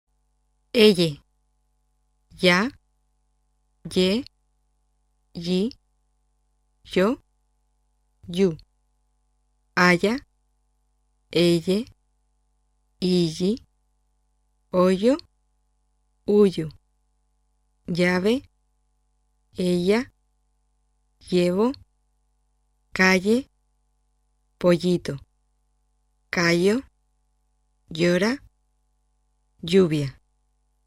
【λ】是舌前硬腭边擦浊辅音。